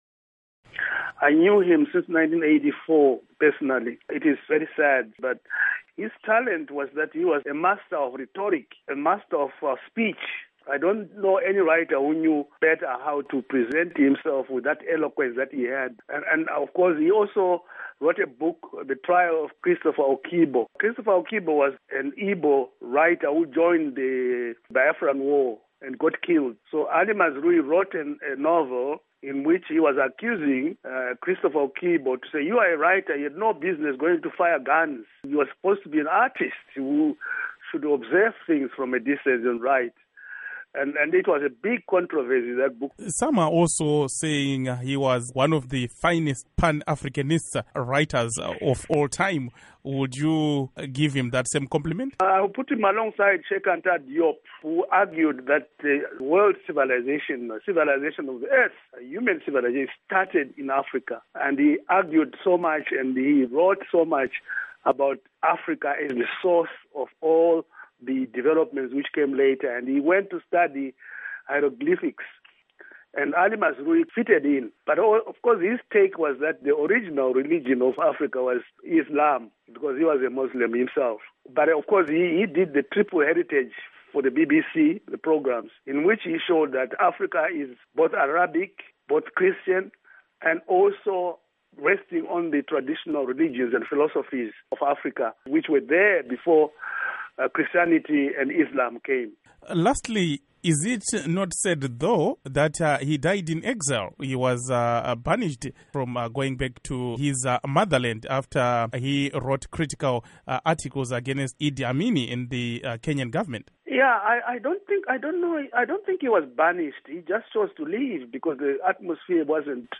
Interview with Chenjerai Hove on Ali Mazrui